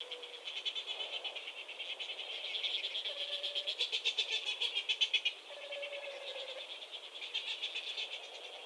Cook’s petrels have a distinctive call, mainly heard at night.
A citizen science project is taking flight across the Auckland-Northland isthmus, calling on locals to report the eerie night-time calls of tītī/Cook’s petrels, better known to some as “flying goats” thanks to their goat-like cries.
Cooks-petrel-Call_1-2.mp3